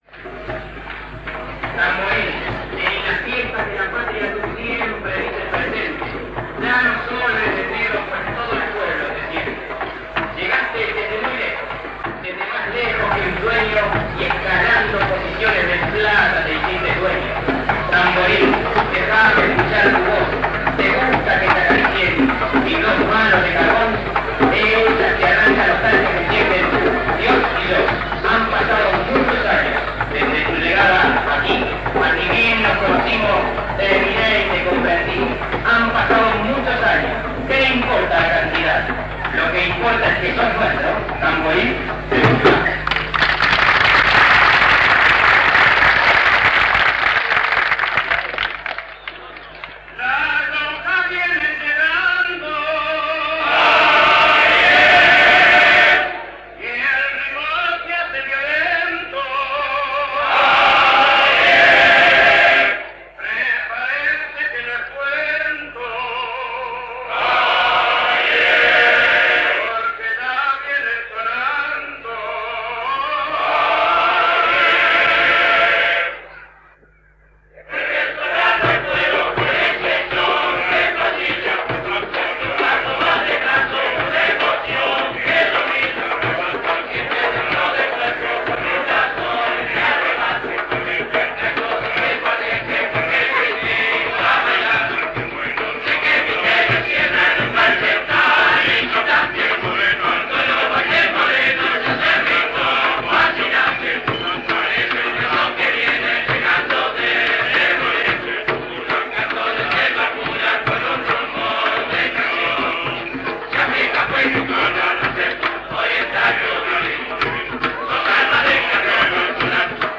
Especie: candombe
Ejecutante, edad, instrumental: Conjunto lubolo “Fantasía Negra”, recitado, coro y tamboriles
Formato original de la grabación: cinta magnética a 9,5 cm/s